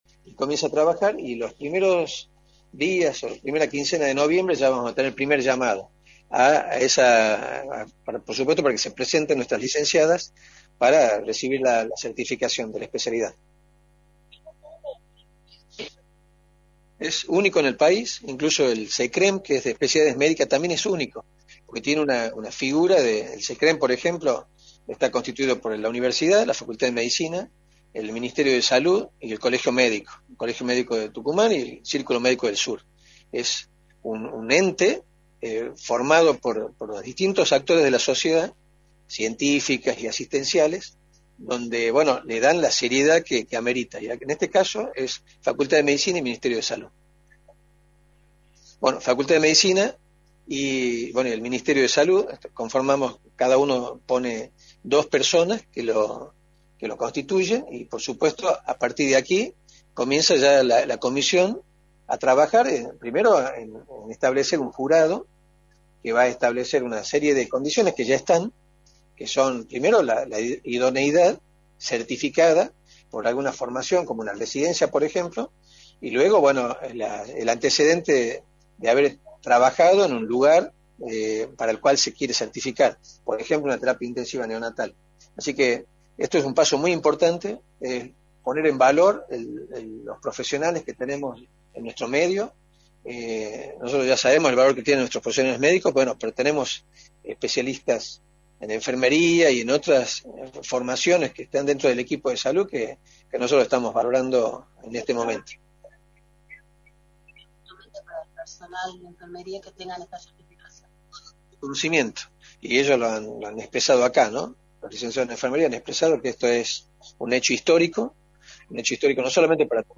Luís Medina Ruíz, Ministro de Salud, remarcó en Radio del Plata Tucumán, por la 93.9, cuál es la situación epidemiológica de la provincia y remarcó el trabajo de los enfermeros en el sistema de salud de la provincia , mediante la certificación de los profesionales.